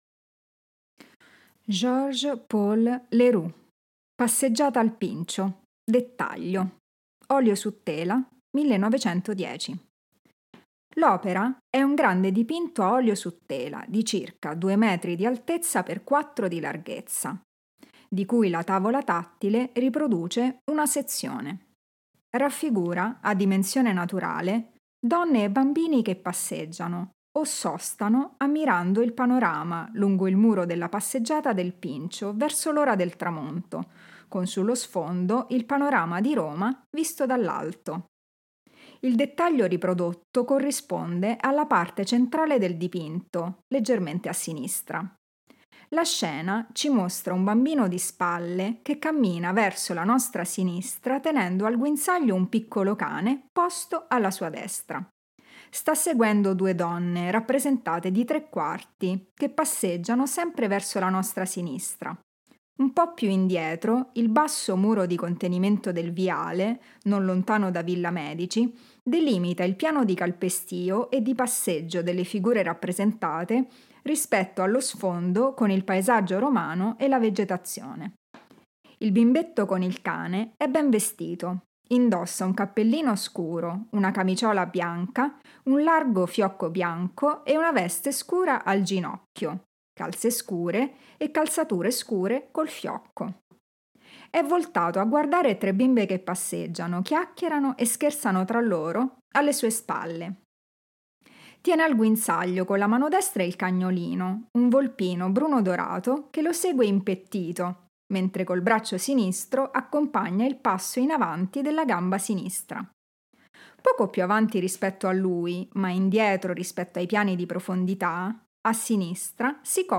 Audio-descrizioni sensoriali: